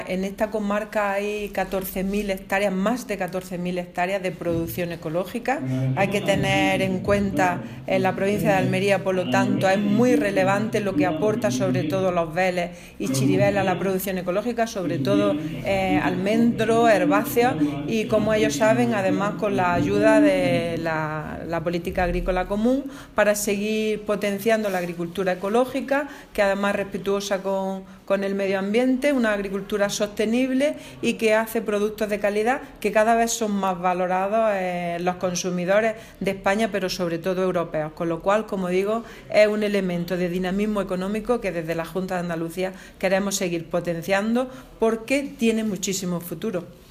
Declaraciones consejera producción ecológica